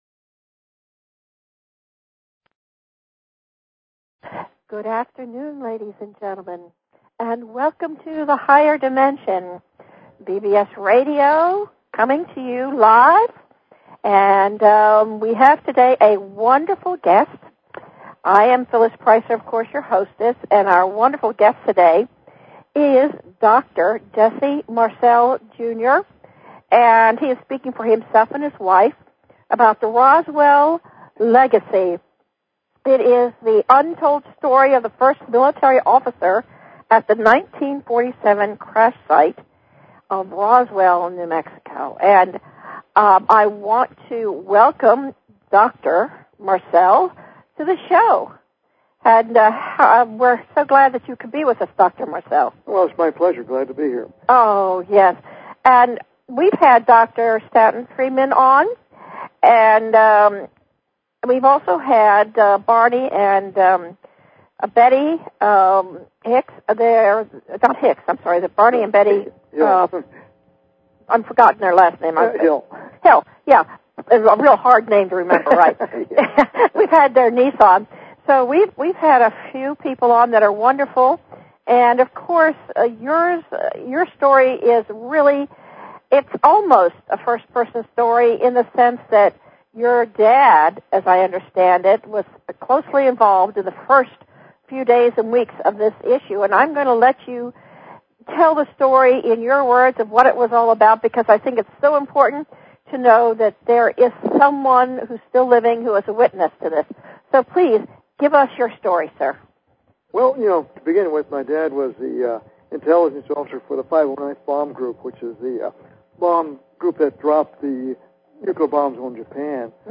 Talk Show Episode, Audio Podcast, Higher_Dimensions and Courtesy of BBS Radio on , show guests , about , categorized as